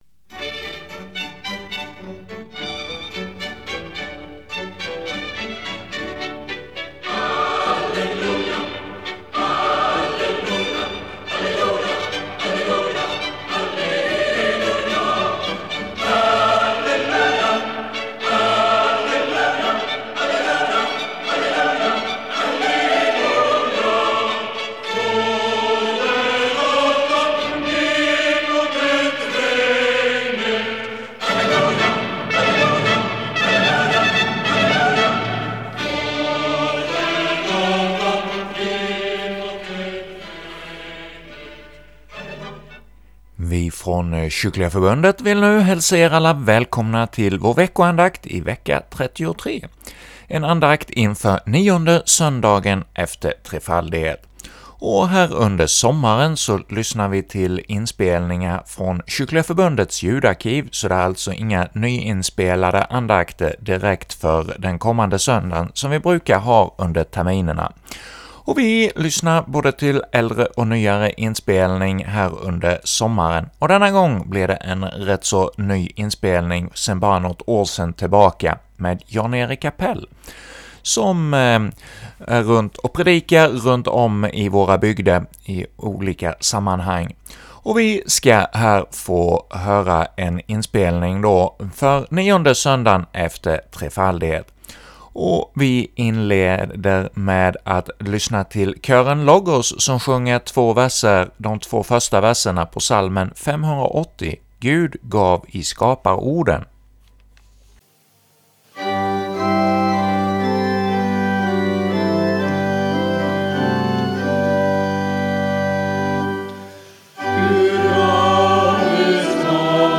leder andakt inför 9 söndagen efter trefaldighet